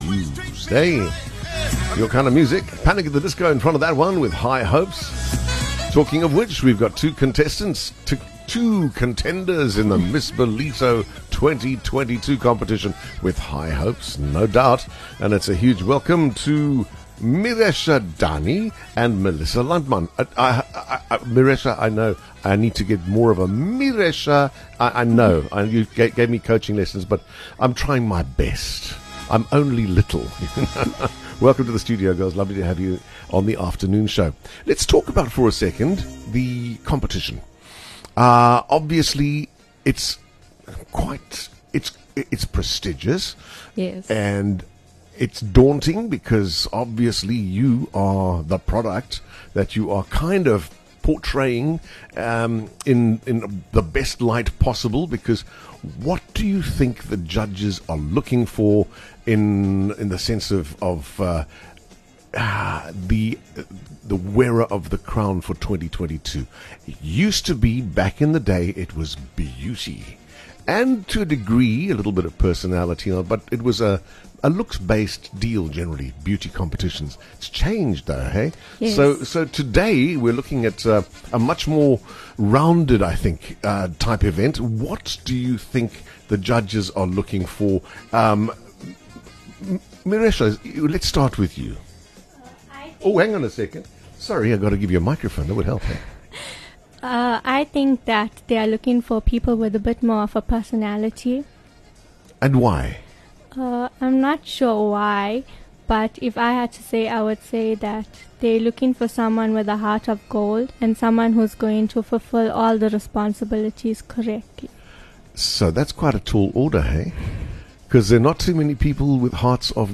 in studio for a quick discussion about their roles in the competition